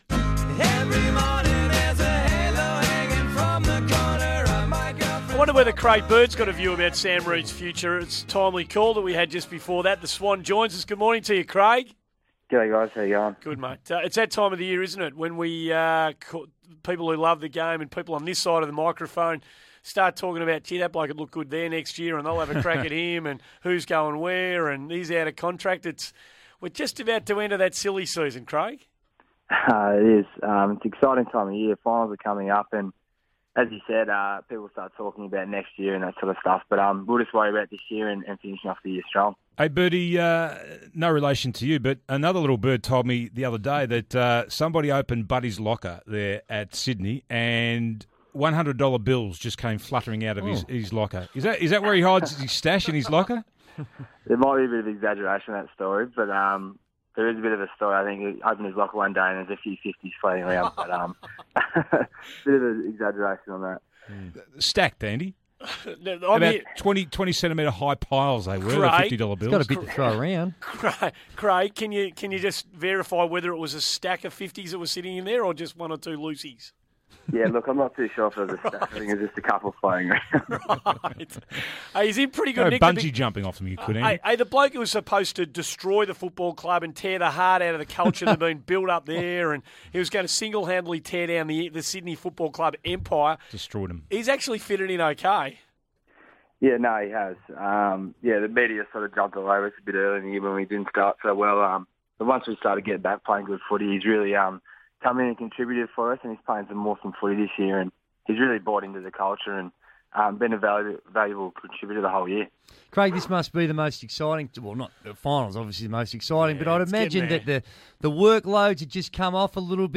Sydney Swans midfielder Craig Bird appeared on 1116SEN's Morning Glory program on Tuesday August 12, 2014